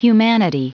Prononciation du mot humanity en anglais (fichier audio)
Prononciation du mot : humanity